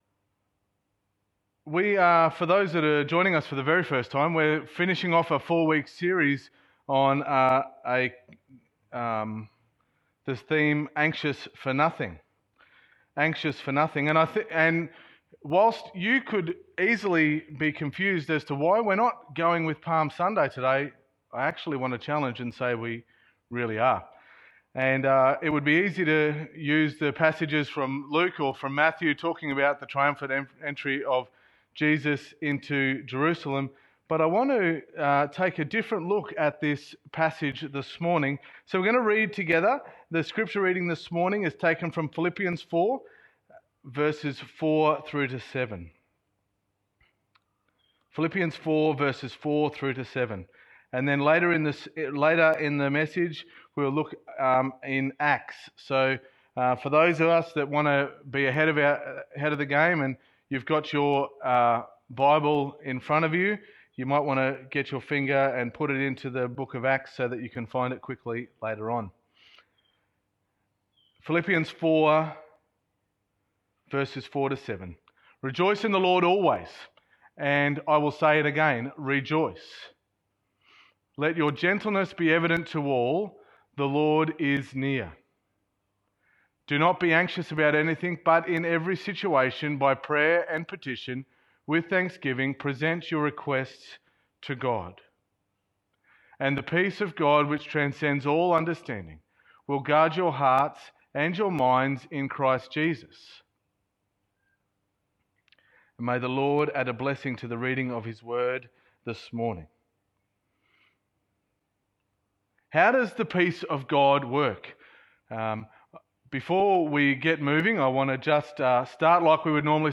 Sermon 05.04.20